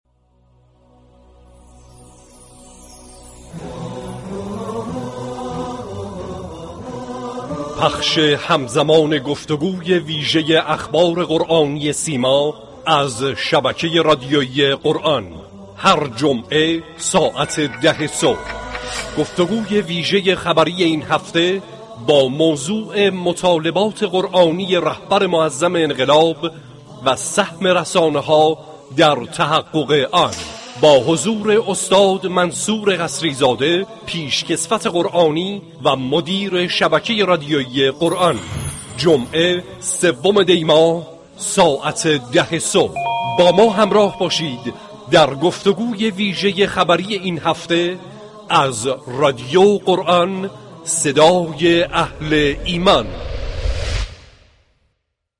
گفت‌و‌گوی ویژه اخبار قرآنی